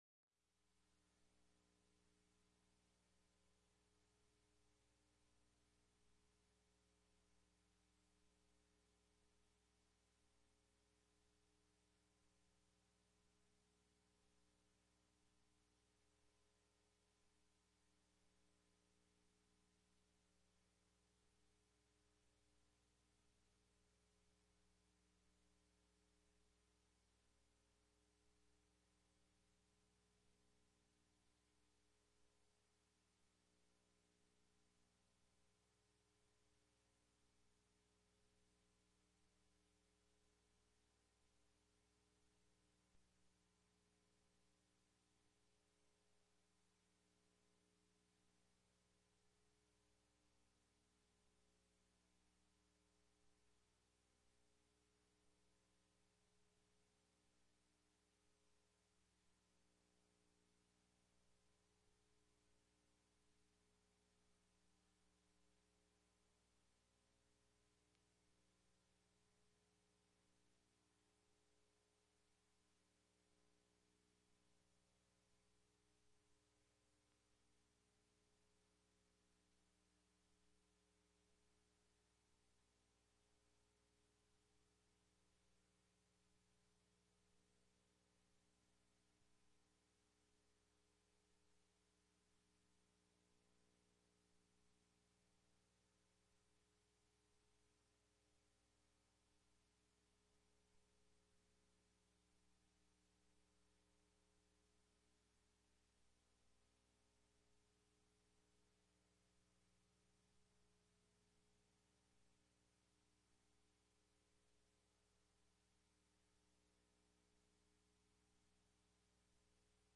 10_9_22-Sermon.mp3